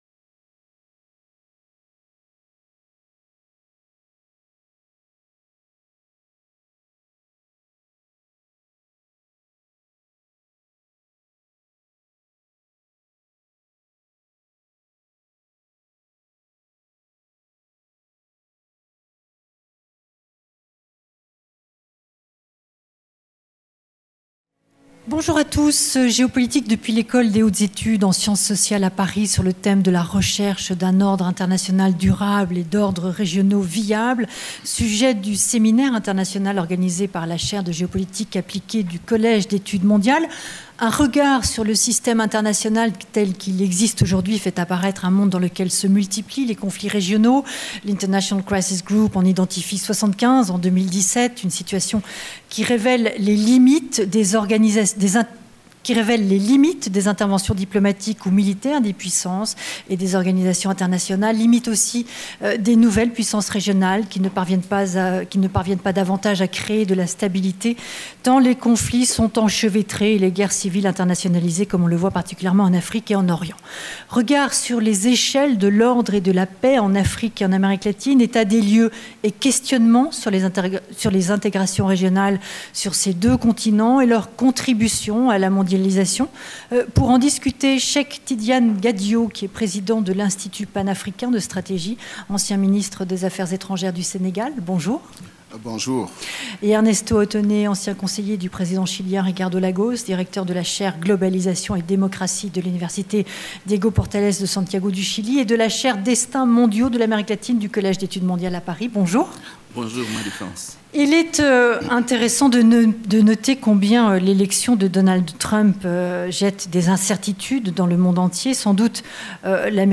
Table ronde 3 – Les échelles de l’ordre et de la paix en Afrique et en Amérique latine | Canal U